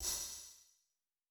TC PERC 03.wav